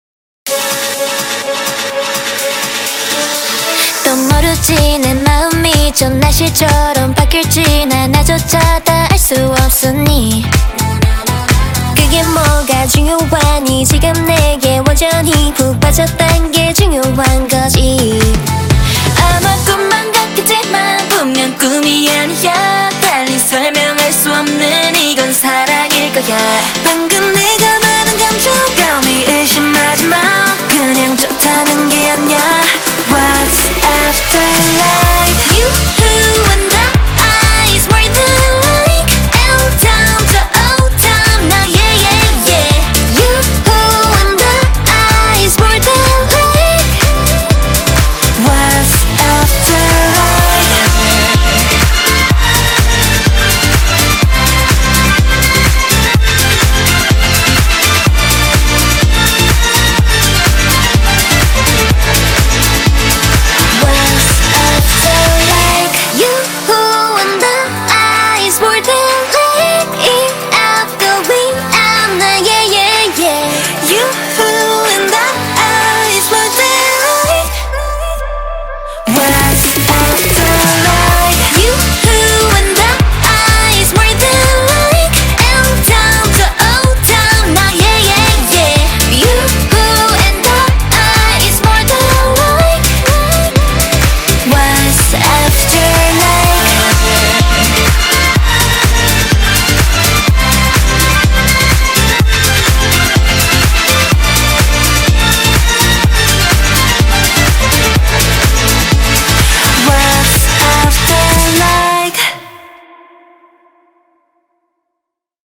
BPM63-125
Audio QualityPerfect (High Quality)